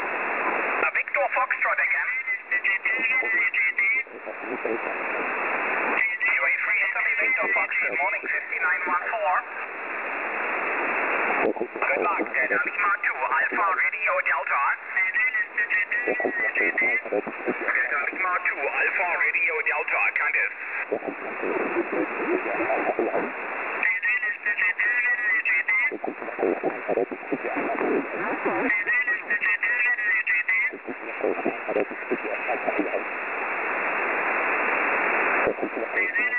WEB-SDR Aufnahmen von verschieden Standorten